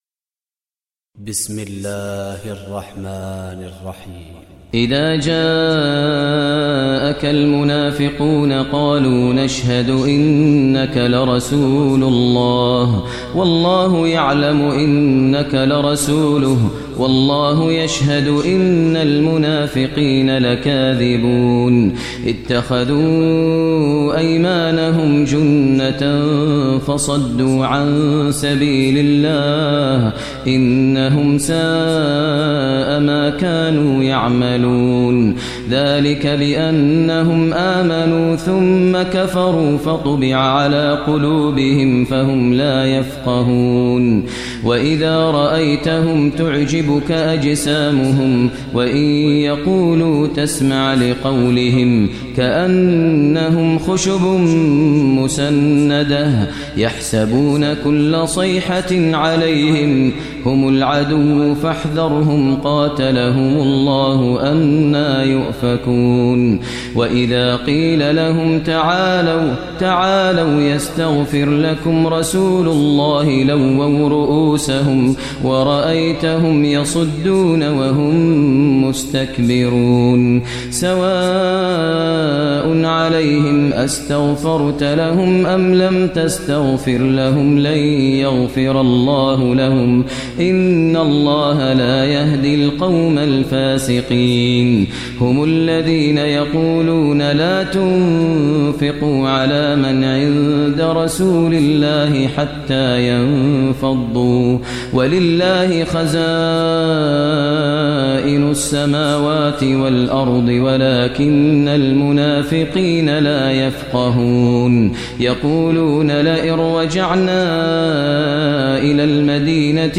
Surah Al-Munafiqun Recitation by Maher al Mueaqly
Surah Al-Munafiqun, listen online mp3 tilawat / recitation in Arabic in the voice of Imam e Kaaba Sheikh Maher al Mueaqly.